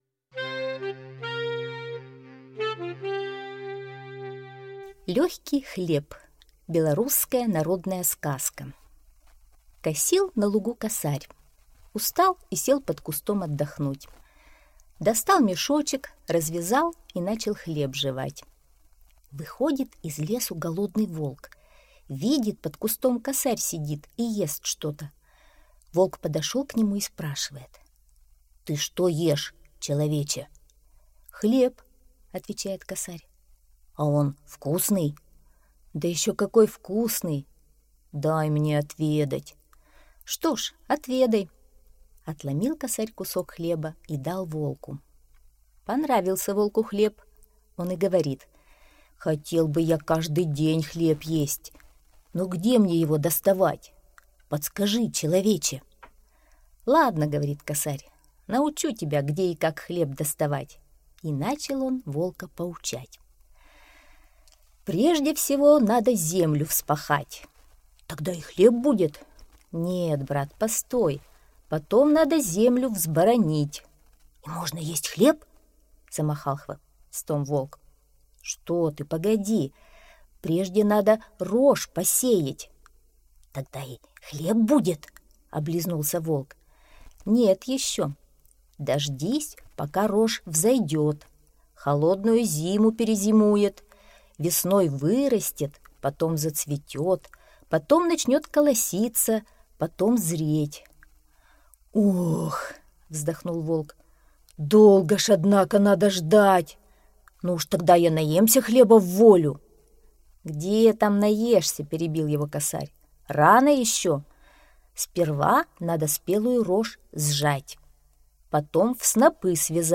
Легкий хлеб - белорусская аудиосказка. Сказка про волка, который искал себе еду.